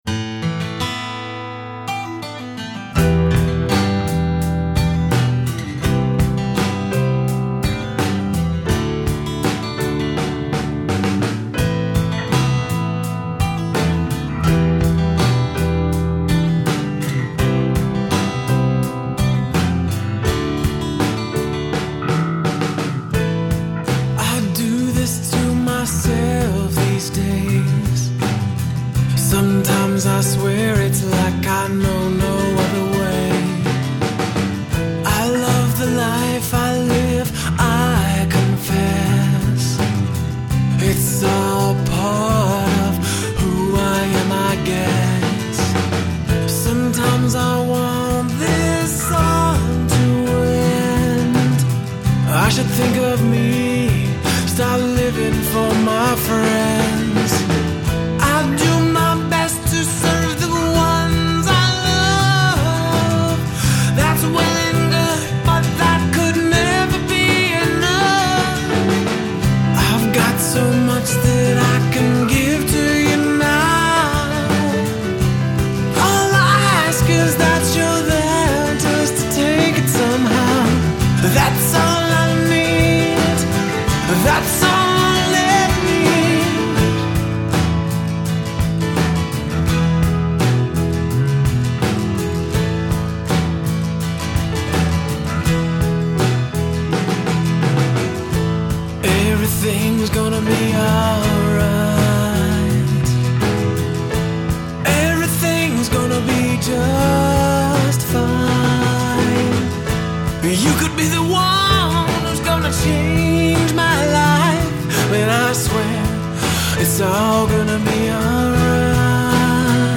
Guitar, Dobro, Vocals
Drums
Bass Guitar
Piano